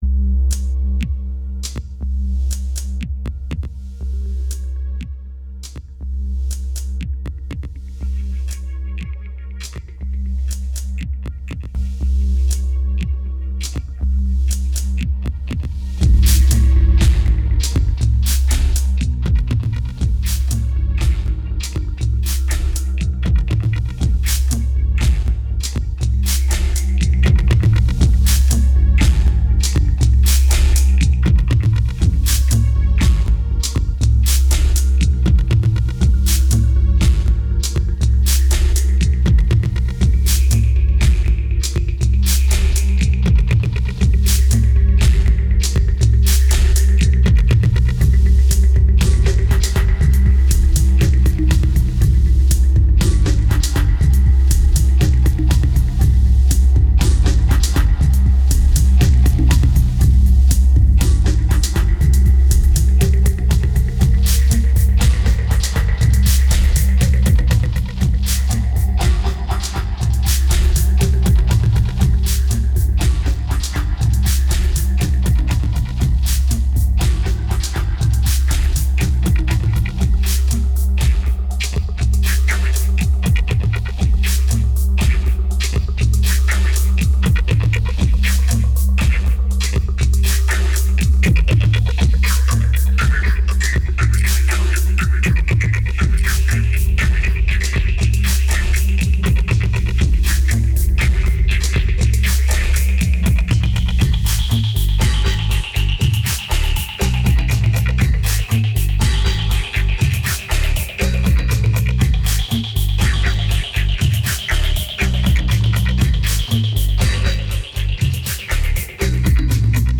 2270📈 - -19%🤔 - 81BPM🔊 - 2009-04-26📅 - -268🌟